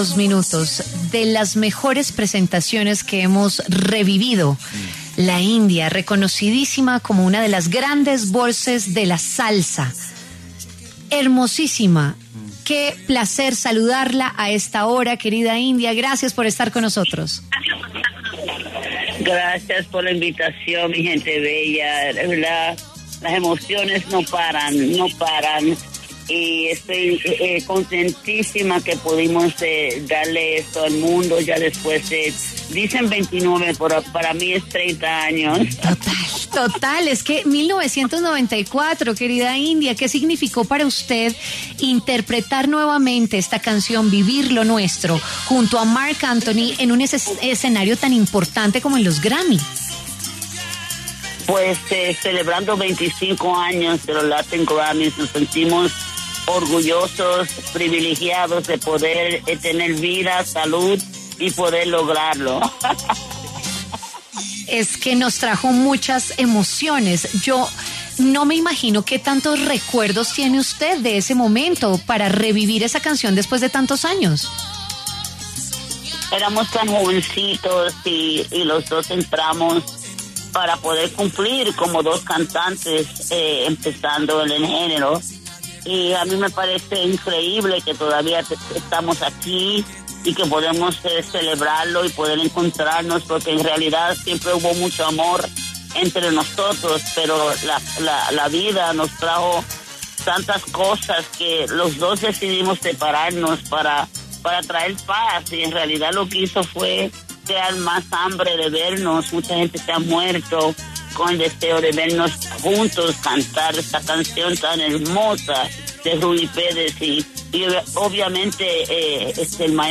La cantante Linda Bell Viera, mejor conocida como ‘La India’, habló en primicia con W Fin de Semana sobre su emotivo reencuentro con Marc Anthony para interpretar ‘Vivir lo nuestro’ en los Grammy Latino 2024 tras casi 30 años.